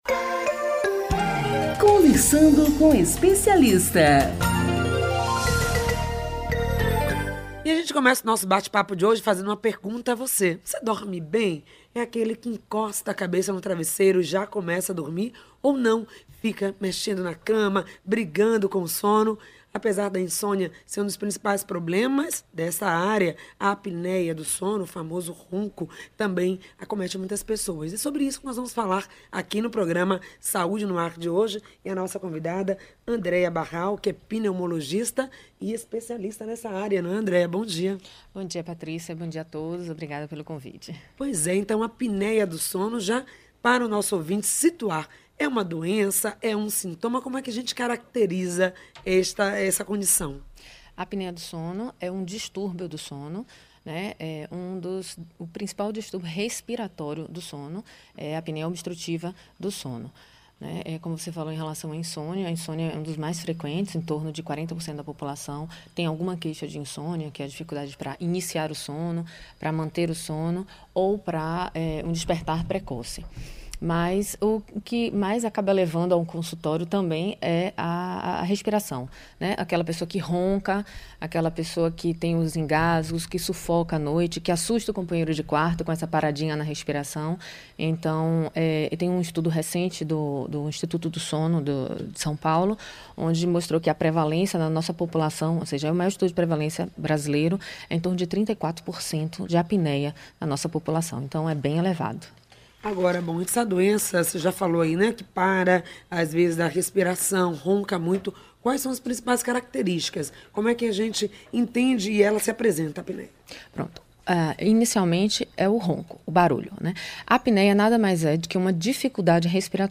O programa exibido pela Rádio AM 840 (em 22.01.16 das 10 às 11h – Horário de verão) abordou assuntos como: cauas, sintomas, tratamento, onde buscar ajuda, e preveção da doença.